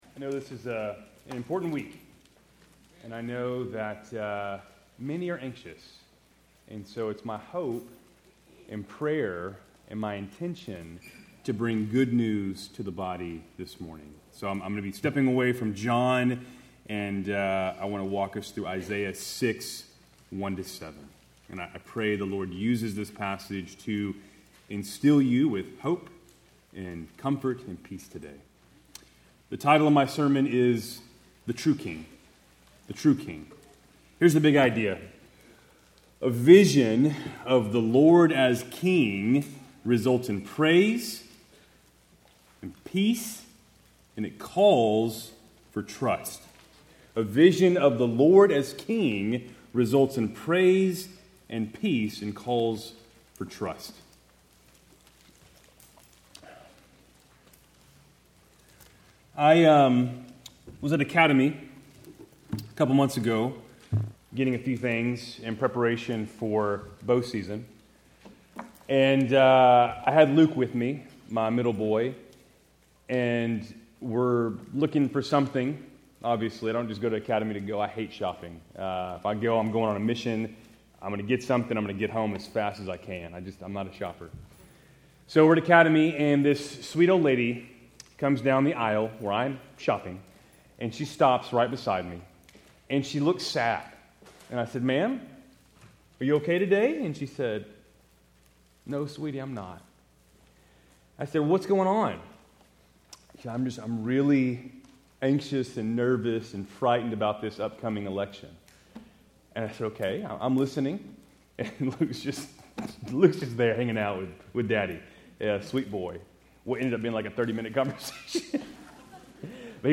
Keltys Worship Service, November 3, 2024